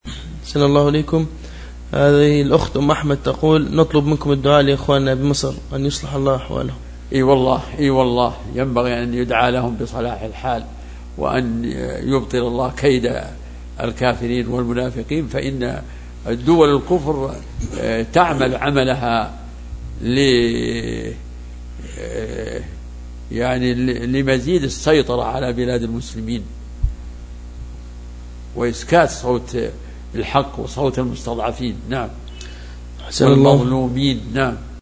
دعاء الشيخ لأهل مصر